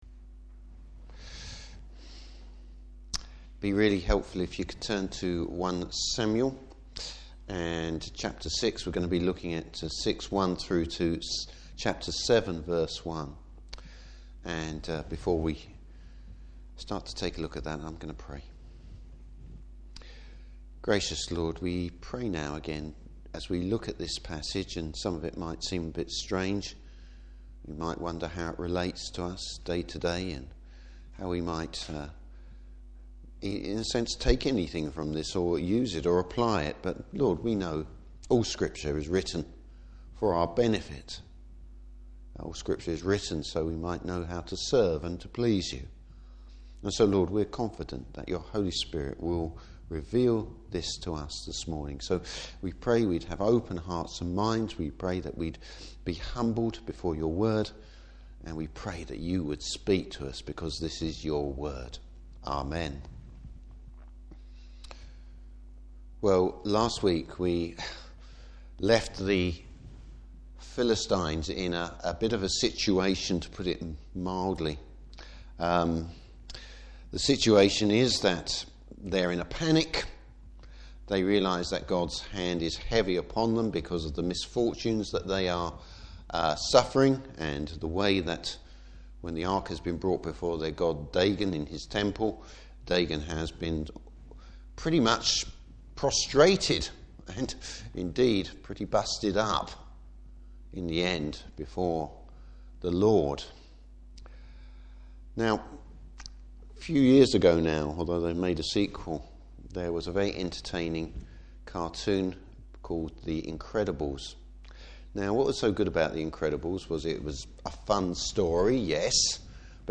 Service Type: Evening Service Has the lesson been learned?